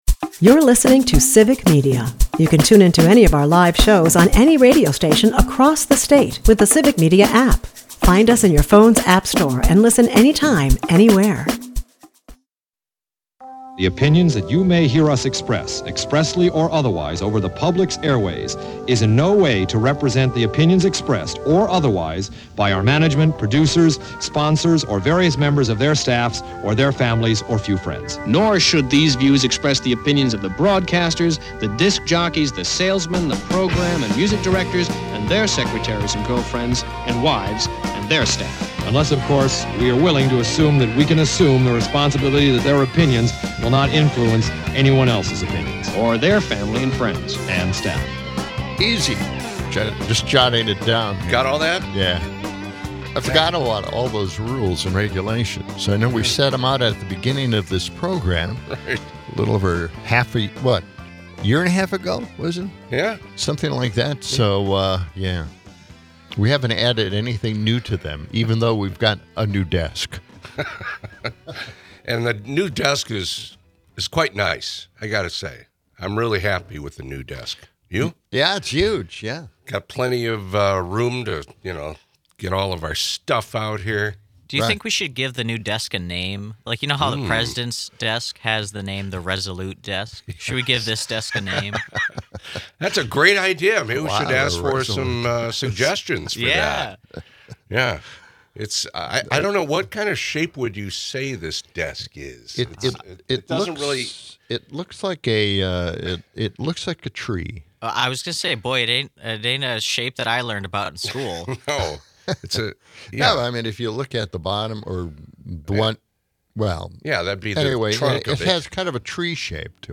We also chat with a caller about the firehose of misinformation, and get a Canadian's take on the US's economic insanity. Wrapping up the first hour, the guys talk more about the deteriorating US-Canada relationship, and our neighbor's vow to become completely economically independent from the US.